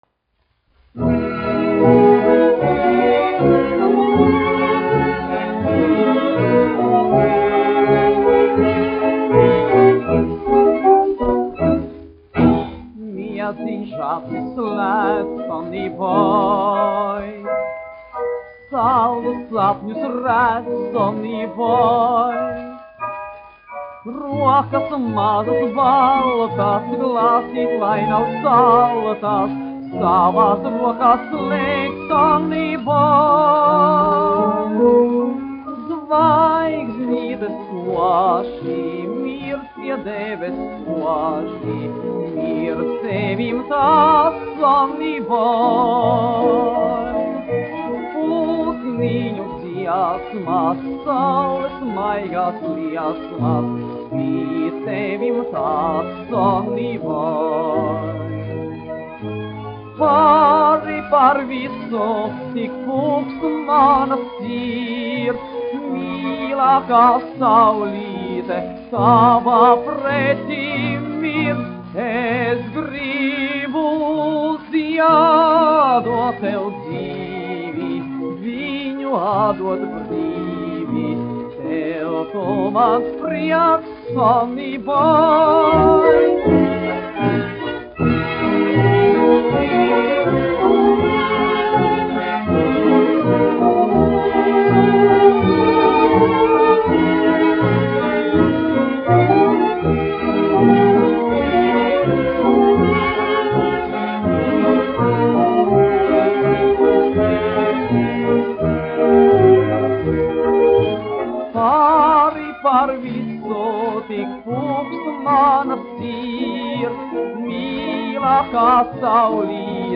1 skpl. : analogs, 78 apgr/min, mono ; 25 cm
Fokstroti
Populārā mūzika
Skaņuplate
Latvijas vēsturiskie šellaka skaņuplašu ieraksti (Kolekcija)